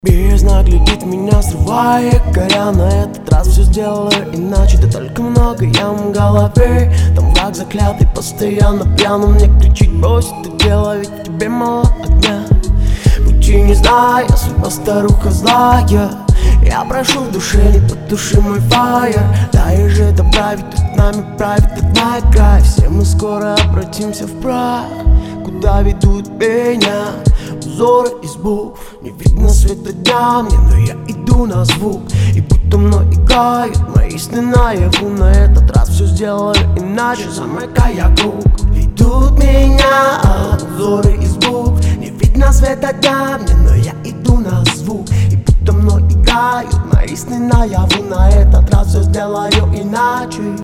Вокал во многих моментах немелодичный, стоит больше думать о гармонии с музыкой, а то слишком настойчив выстраиваешь вокалом отдельную линию.